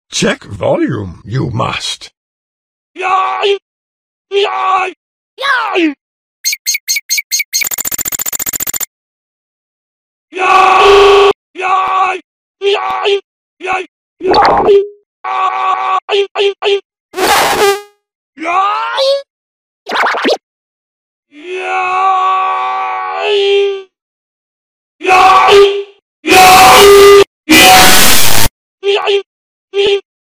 JANGO FETT Lego Death Sound sound effects free download
JANGO FETT Lego Death Sound Variations